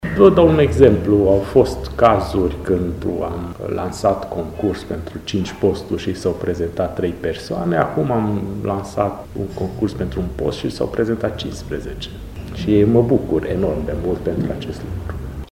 insert-primar.mp3